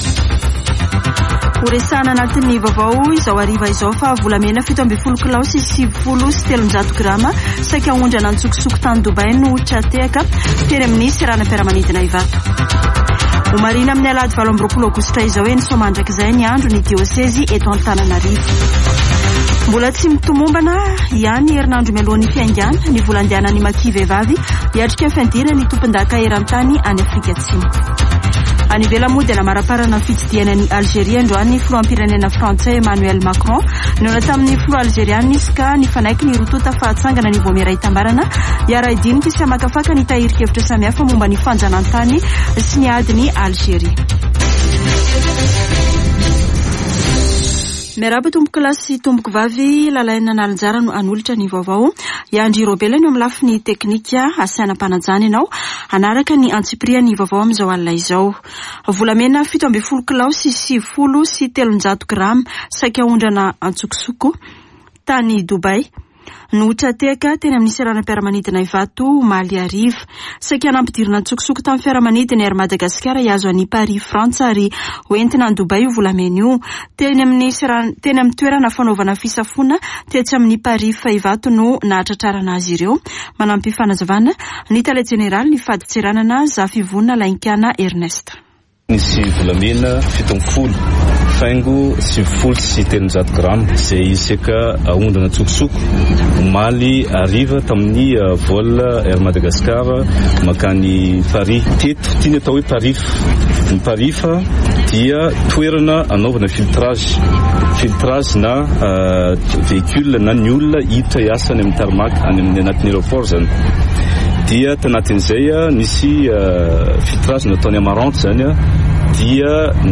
[Vaovao hariva] Zoma 26 aogoistra 2022